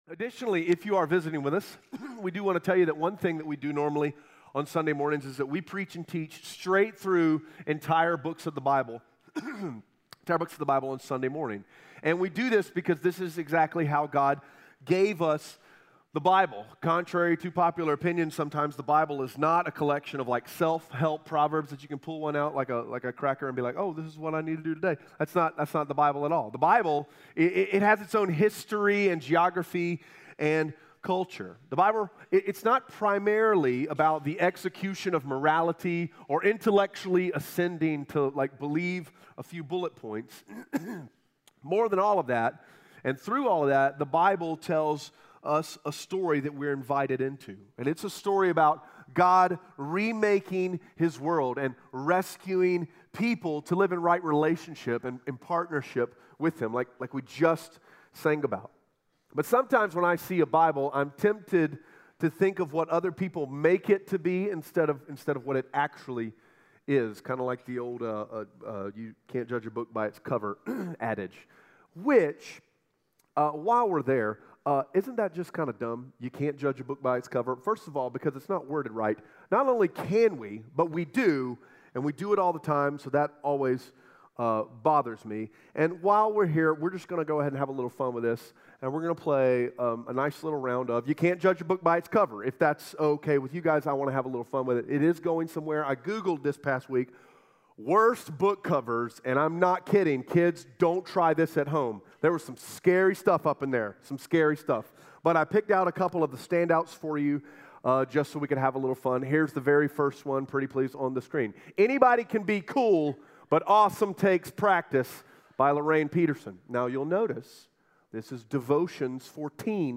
Romans 8:1-2 Audio Sermon Notes (PDF) Onscreen Notes Ask a Question *We are a church located in Greenville, South Carolina.